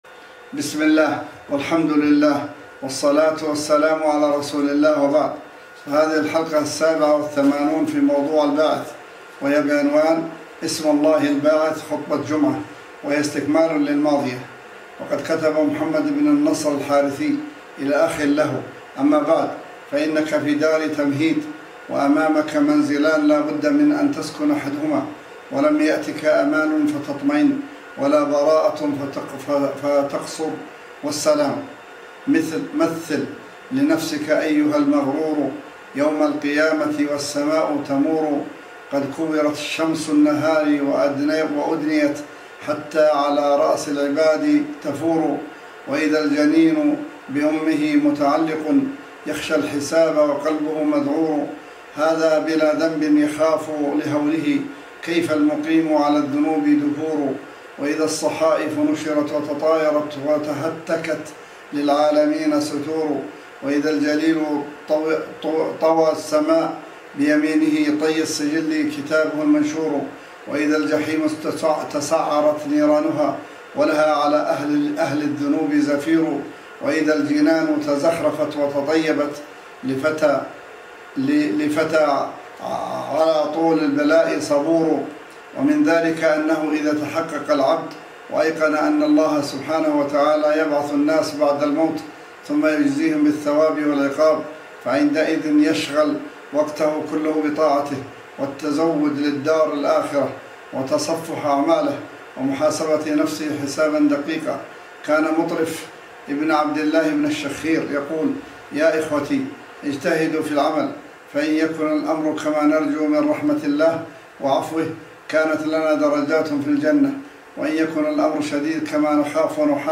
الحلقة السابعة والثمانون في موضوع (الباعث) وهي بعنوان :           * اسم الله الباعث – خطبة جمعة :